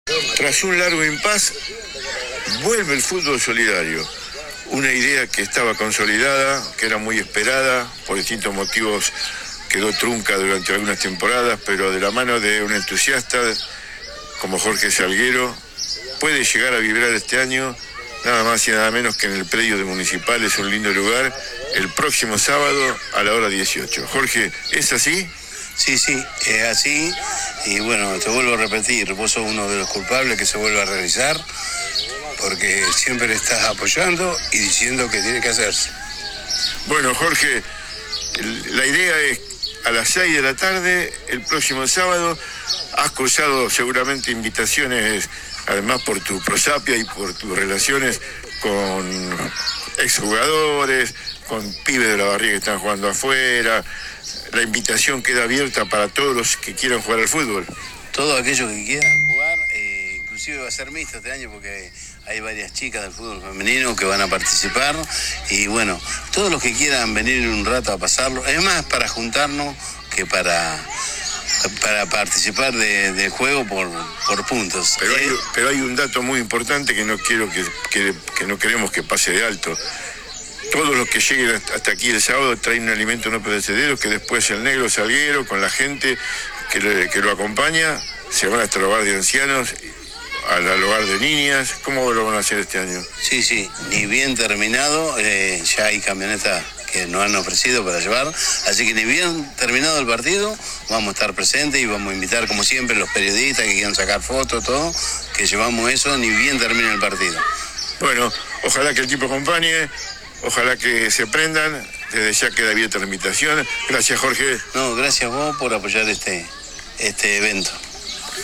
AUDIO DE LA ENTREVISTA